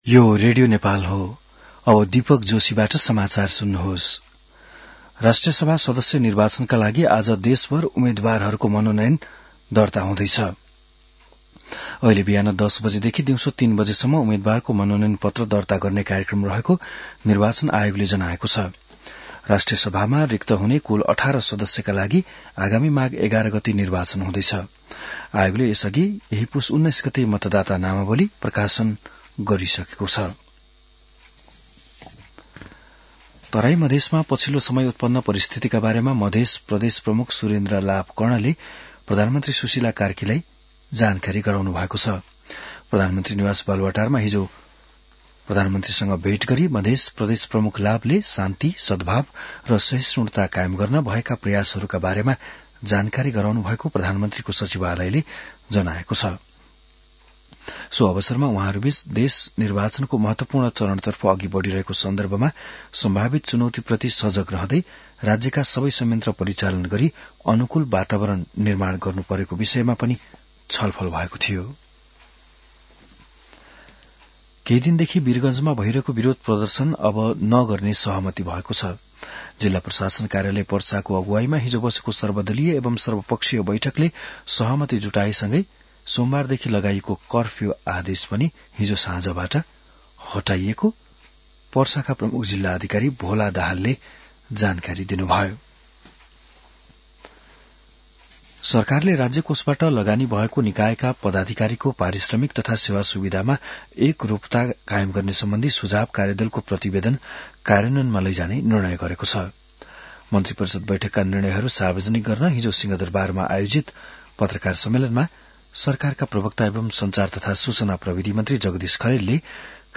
An online outlet of Nepal's national radio broadcaster
बिहान १० बजेको नेपाली समाचार : २३ पुष , २०८२